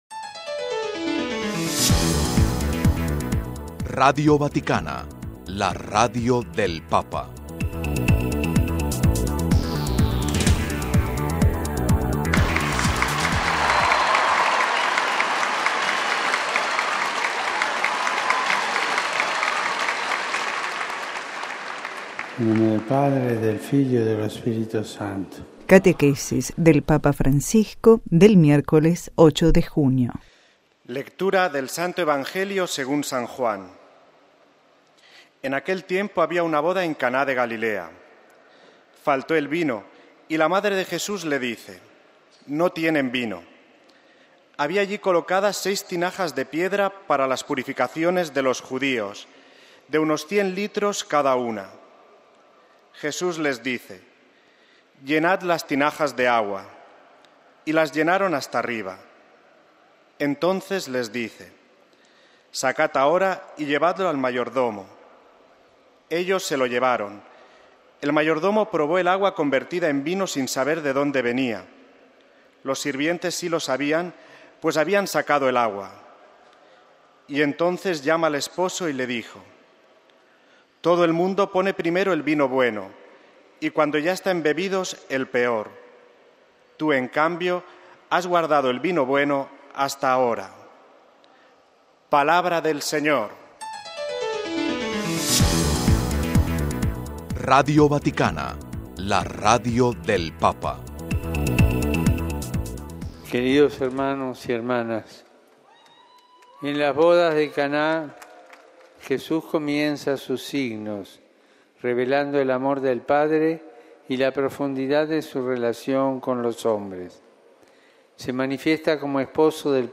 Las bodas de Caná: primer signo de la misericordia de Jesús. Catequesis del Papa en español
Las bodas de Caná, punto de partida de la reflexión del Papa Francisco, fue la narración evangélica que nos llevó a reflexionar sobre la misericordia en este miércoles 8 de junio. A continuación, el texto y audio completo del resumen de la catequesis que el Santo Padre pronunció en español